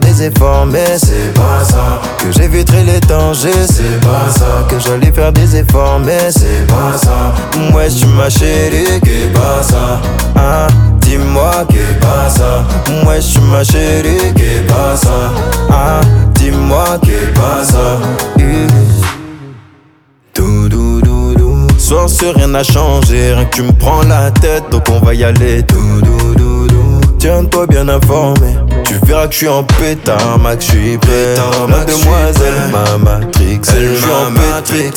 Скачать припев
Afro-Beat African